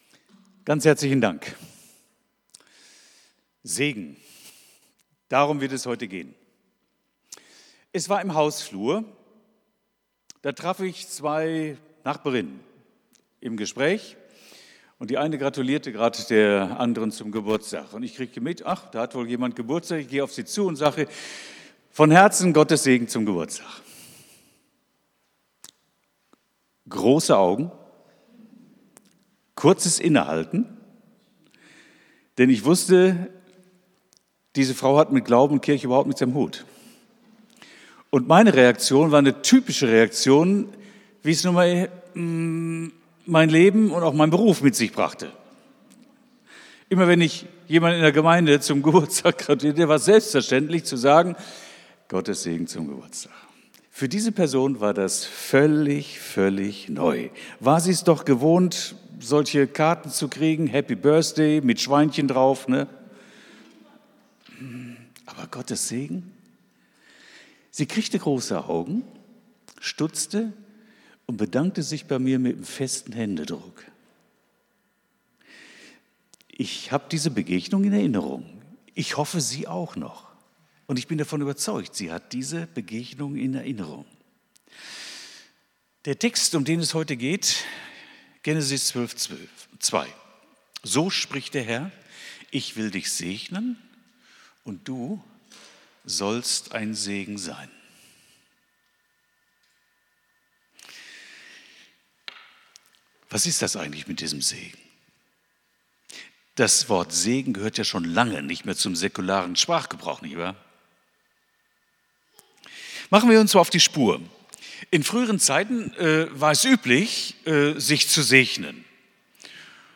Predigt vom 12.10.2025